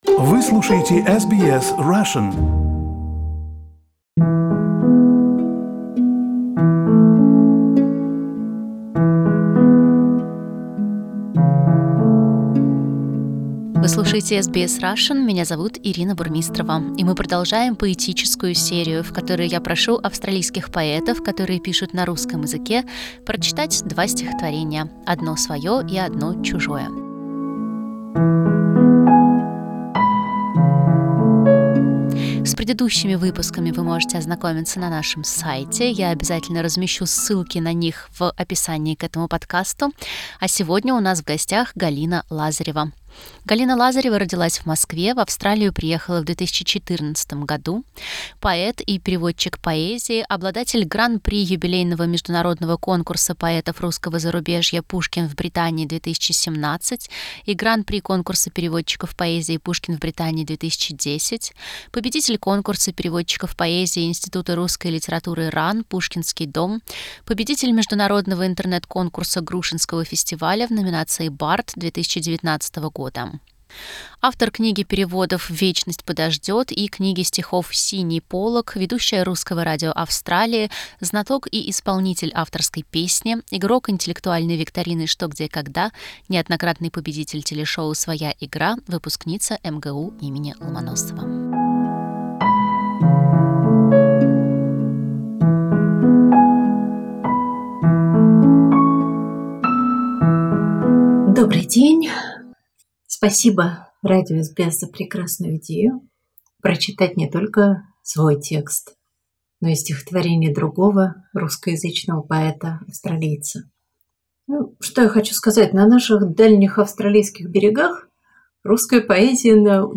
В этой рубрике мы просим австралийских поэтов прочесть два стихотворения: одно свое и одно чужое.